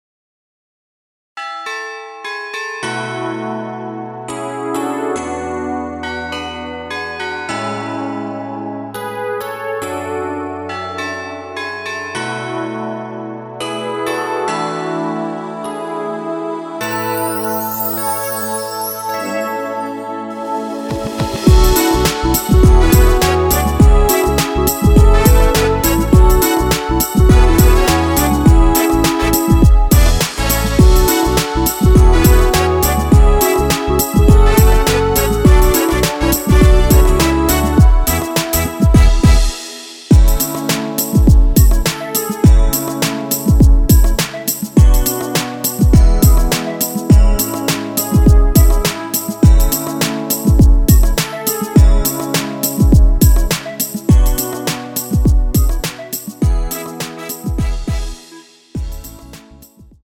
원키에서(-1)내린 MR입니다.
◈ 곡명 옆 (-1)은 반음 내림, (+1)은 반음 올림 입니다.
앞부분30초, 뒷부분30초씩 편집해서 올려 드리고 있습니다.